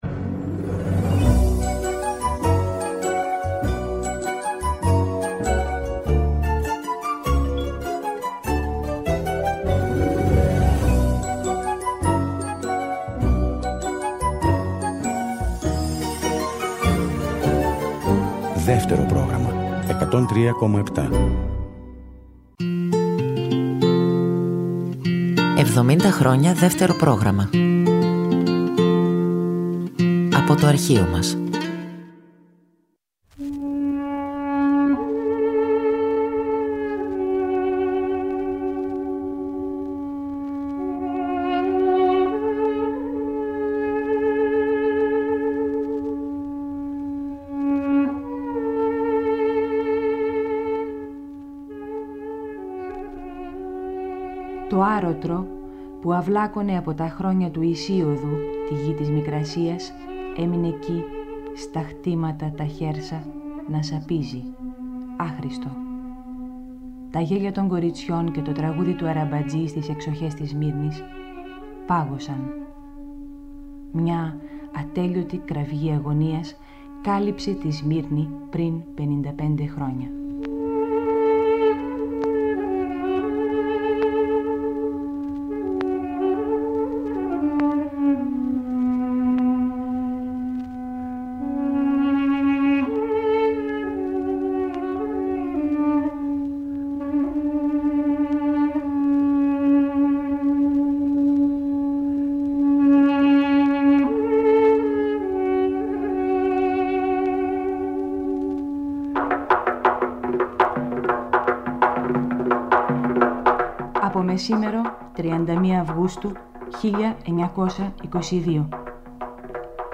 Η Μαριάννα Κορομηλά σε ένα ραδιοφωνικό ντοκυμαντέρ με ιστορικά στοιχεία, ηχητικά ντοκουμέντα και μαρτυρίες για τα 55 χρόνια από τη Μικρασιατική καταστροφή.
Κάθε Κυριακή απόγευμα στις 18:00 ακούμε εκπομπές λόγου και μουσικής που είχαν μεταδοθεί παλιότερα από το Δεύτερο Πρόγραμμα.